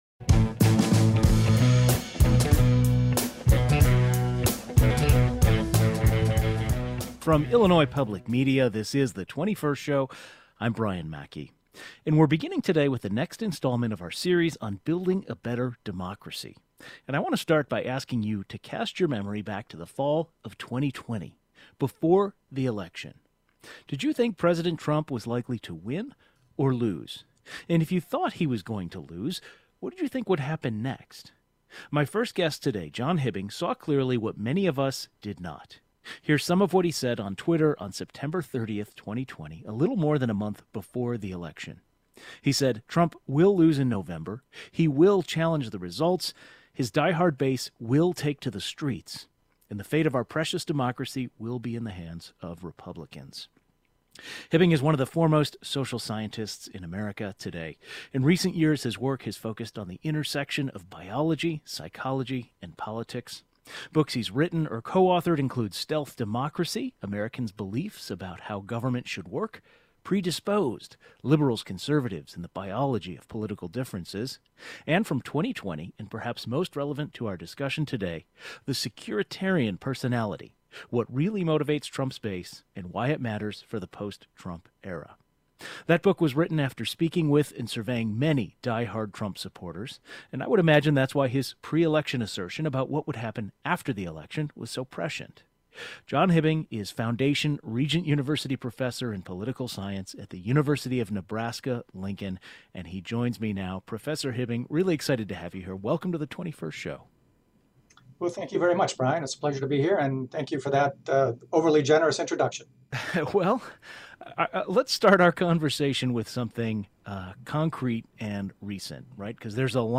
Today is the next conversation in our series on Building a Better Democracy.